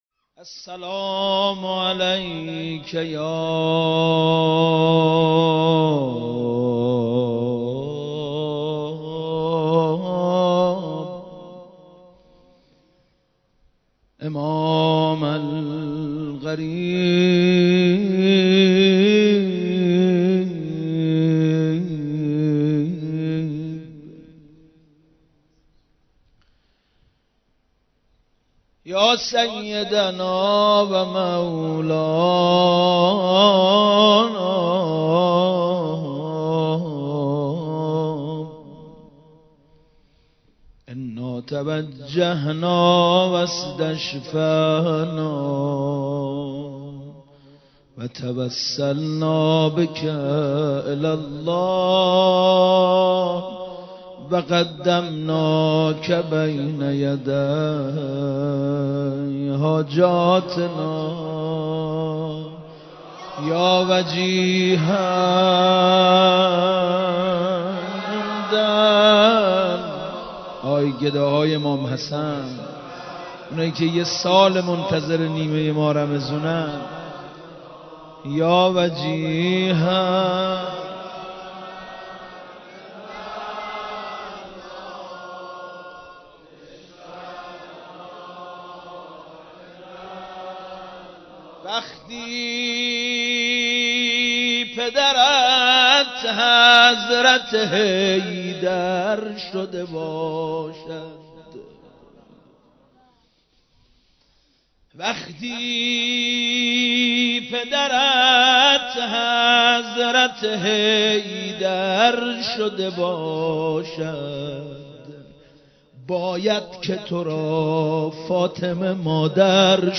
مدیحه سرایی سال ۹۳
سرود سال ۹۳